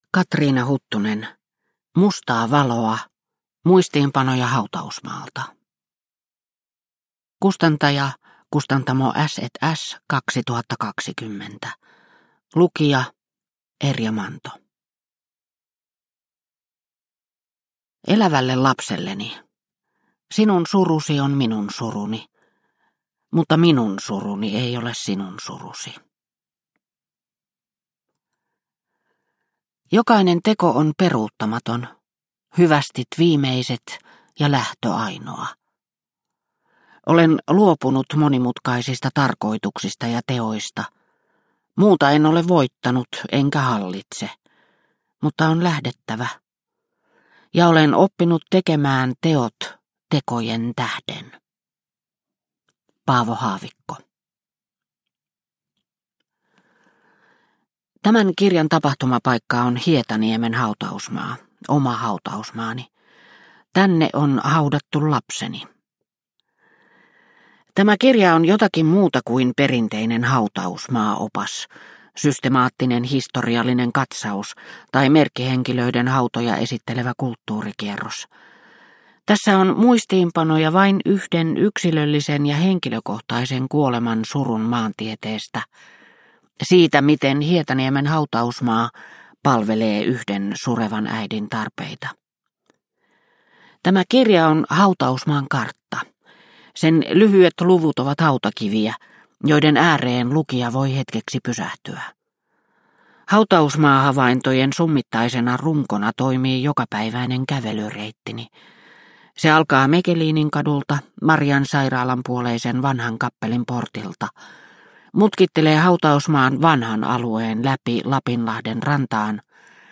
Mustaa valoa – Ljudbok – Laddas ner